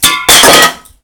Soda Can Shot
can damage destroy distorted funny knockdown knockout loud sound effect free sound royalty free Funny